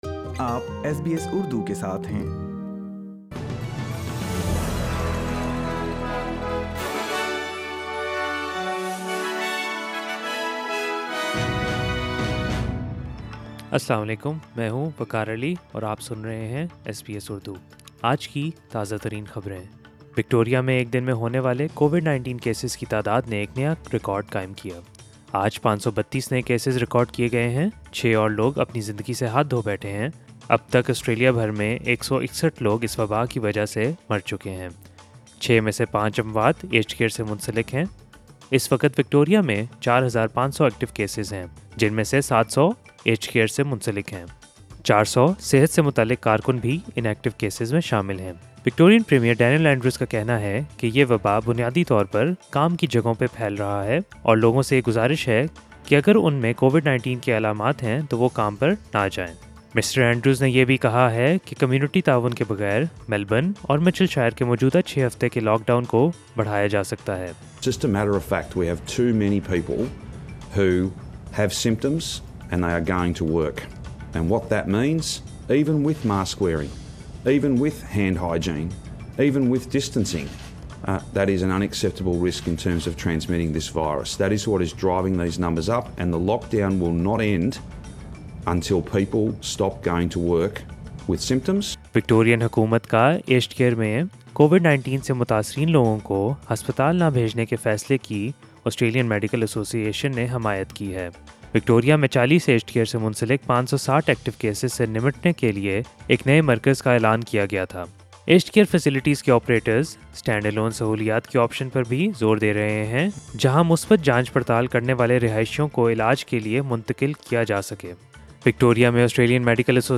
SBS Urdu News 27 July 2020